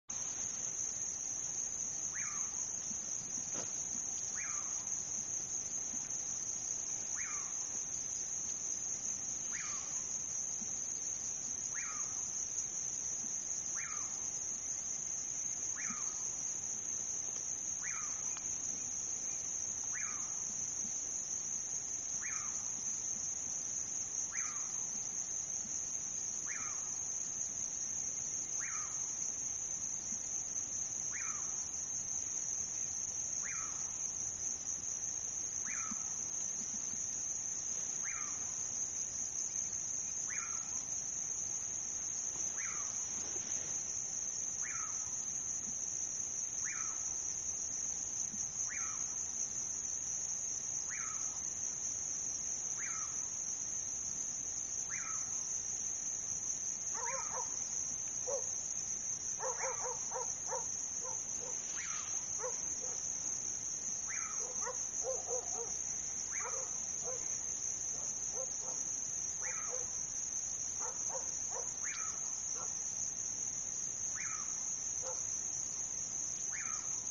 Curiango (Nyctidromus albicollis)
Nombre en inglés: Pauraque
Provincia / Departamento: Entre Ríos
Condición: Silvestre
Certeza: Observada, Vocalización Grabada